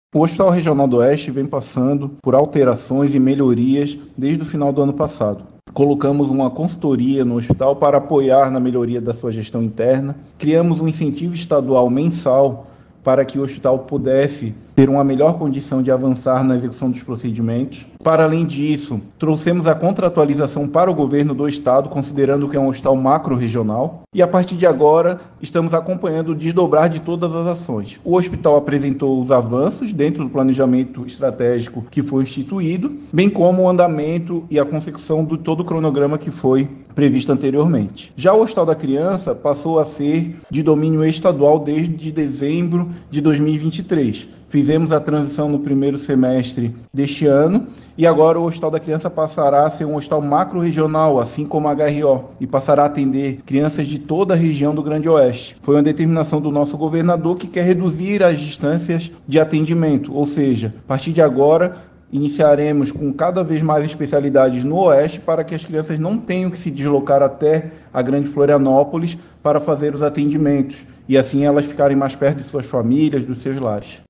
Ouça o que disse o secretário